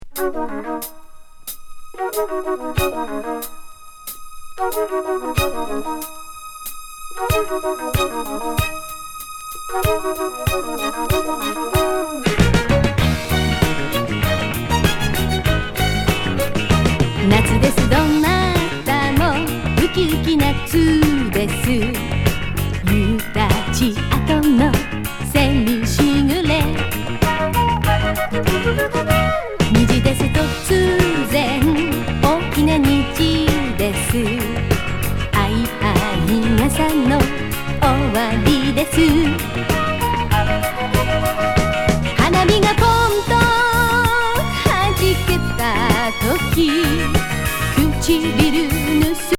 スリリング・ストリングス入ファンク歌謡A面